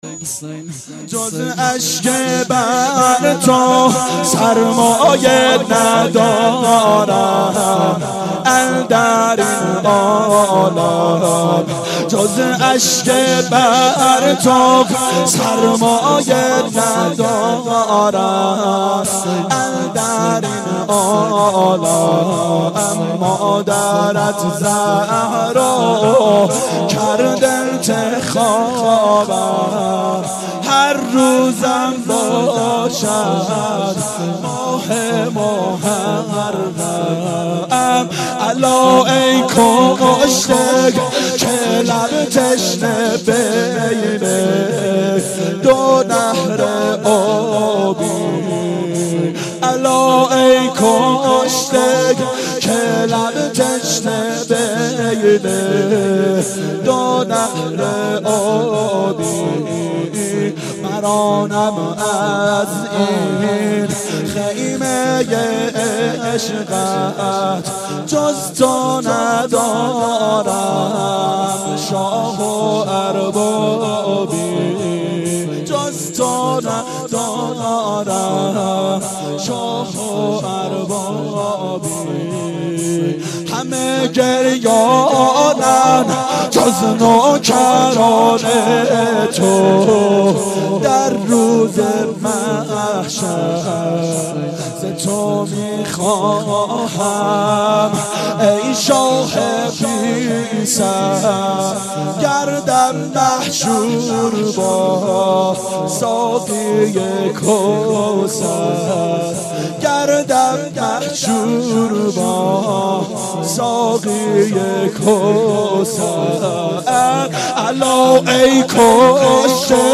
فاطمیه اول 92 هیأت عاشقان اباالفضل علیه السلام منارجنبان